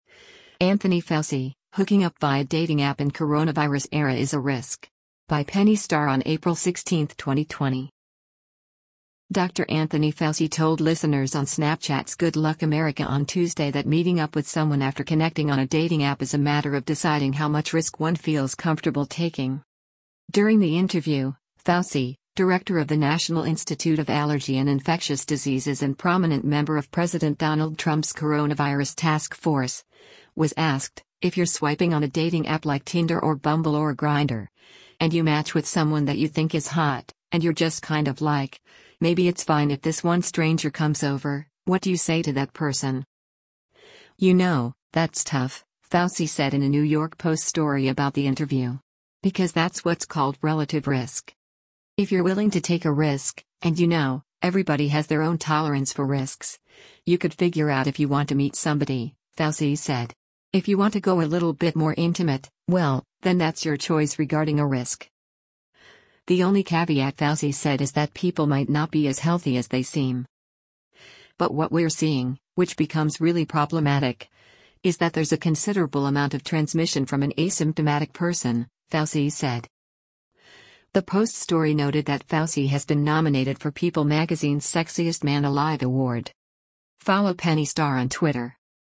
During the interview, Fauci, director of the National Institute of Allergy and Infectious Diseases and prominent member of President Donald Trump’s coronavirus task force, was asked, “If you’re swiping on a dating app like Tinder or Bumble or Grindr, and you match with someone that you think is hot, and you’re just kind of like, ‘Maybe it’s fine if this one stranger comes over,’ what do you say to that person?”